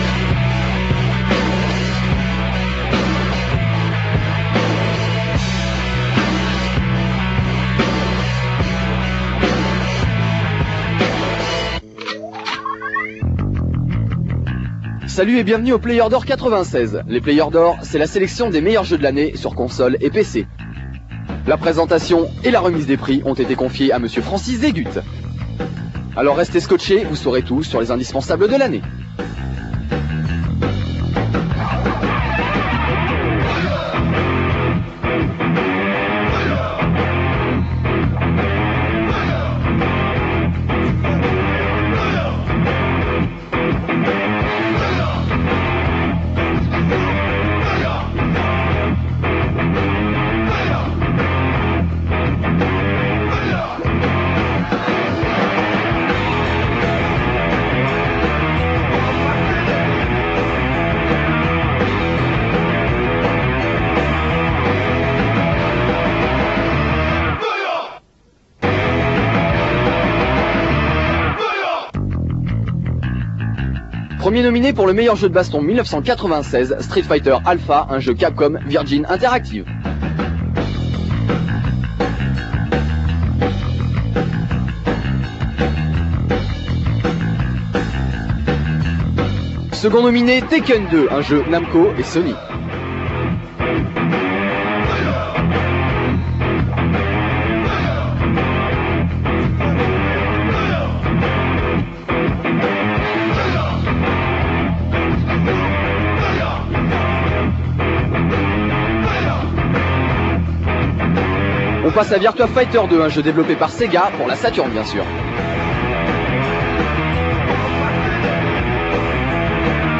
Je propose également 2 version streaming de basse qualité (ce qui permet d'avoir de petits fichiers qui se lancent de façon instantannée, même chez les gens en bas débit) et qui permettent également de se faire une idée de la vidéo avant de la télécharger.
vhspromo_playersdor96.rm